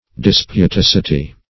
Search Result for " disputacity" : The Collaborative International Dictionary of English v.0.48: Disputacity \Dis`pu*tac"i*ty\, n. [See Dispute , v. i.]